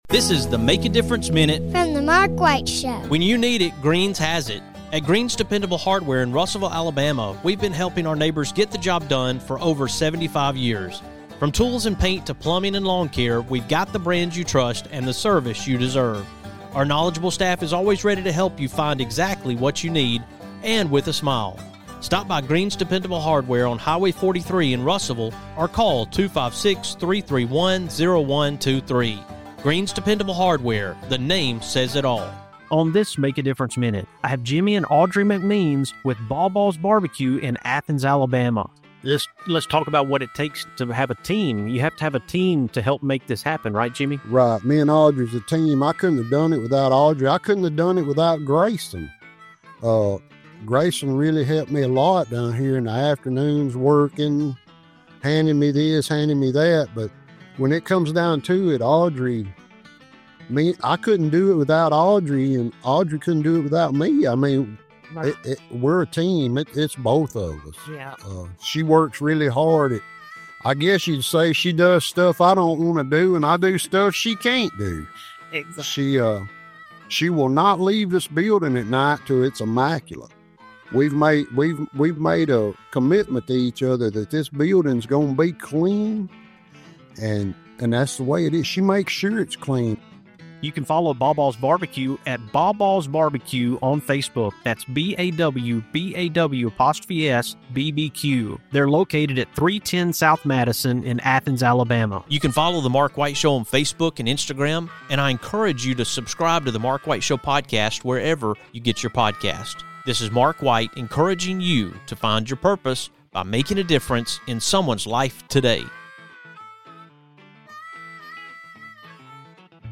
I’m coming to you from BawBaw’s BBQ in Athens, Alabama